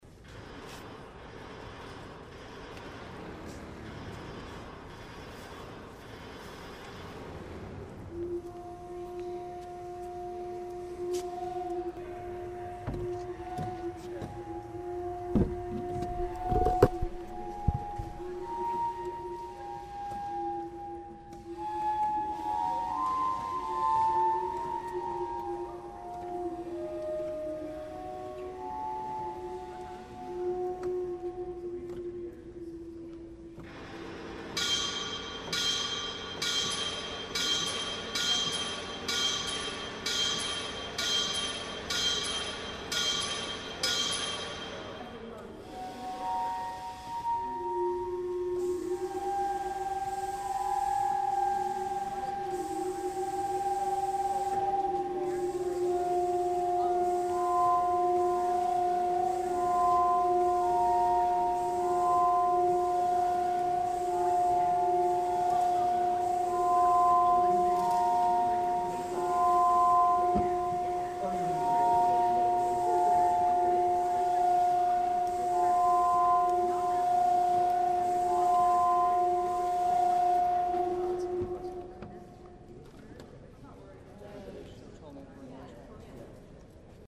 David Byrne's building, 8.8.08